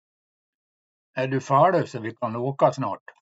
Så här kan fâlu användas i en mening